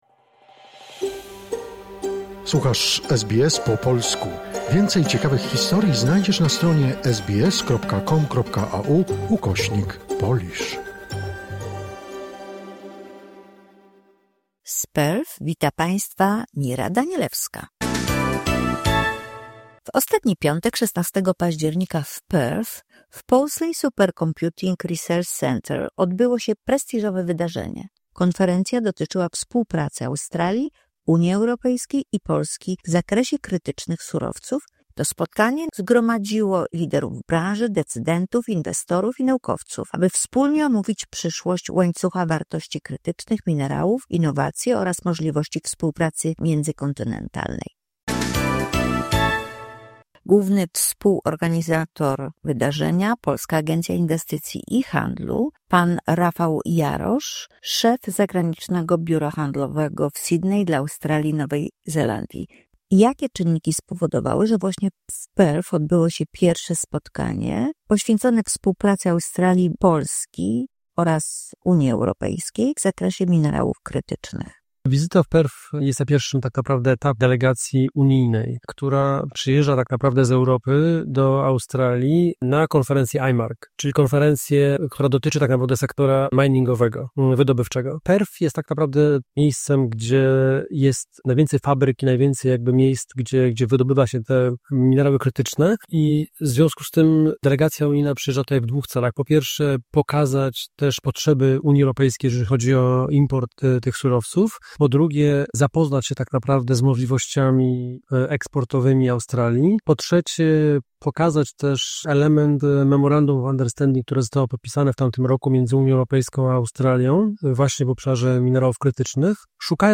Co zapowiada przyszłość w kwestii minerałów krytycznych oraz jakie są możliwości współpracy międzykontynentalnej w pozyskiwaniu takich minerałów? Na konferencji o współpracy Australii, Unii Europejskiej i Polski w Perth